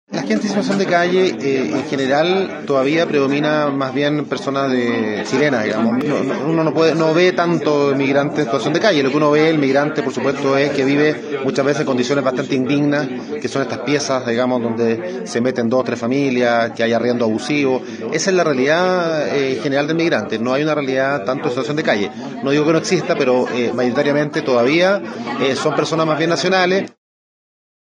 Su alcalde, Rodrigo Delgado, se refirió a la situación de los inmigrantes que puedan encontrarse en esta situación.